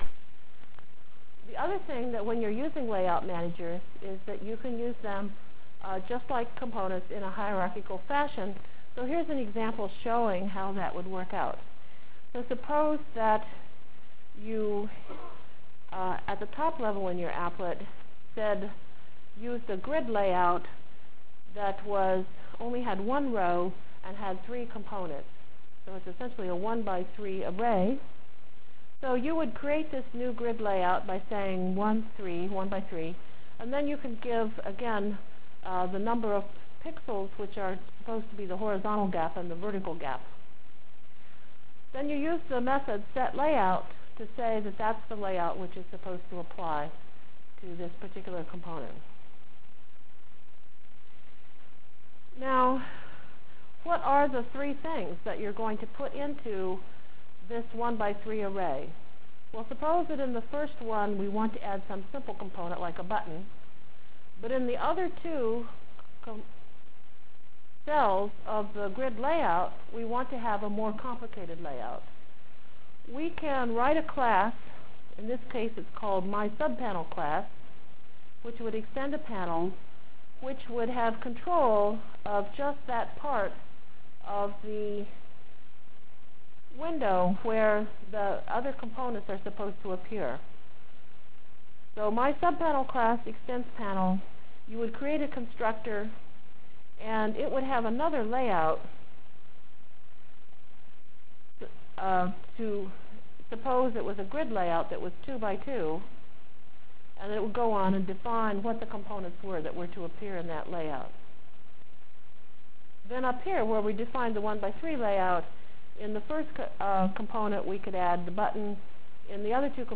From Feb 3 Delivered Lecture for Course CPS616